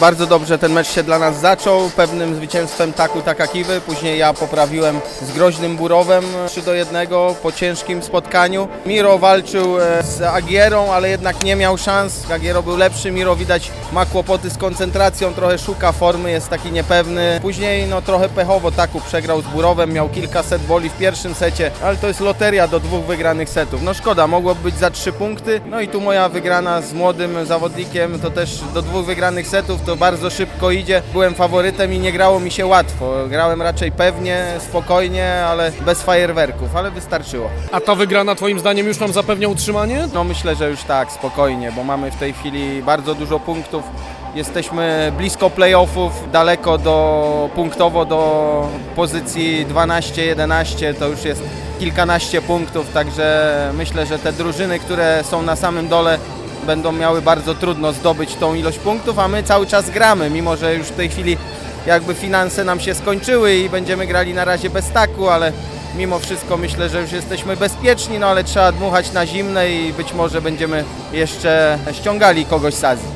Po meczu rozmawialiśmy z Lucjanem Błaszczykiem:
sport-Błaszczyk-po-Bydgoszczy.mp3